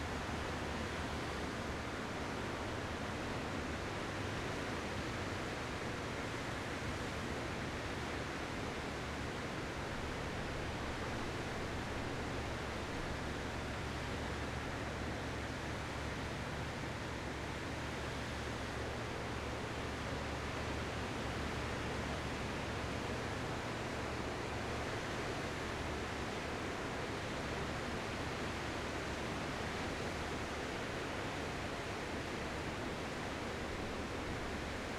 sea.wav